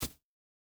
Bare Step Grass Hard D.wav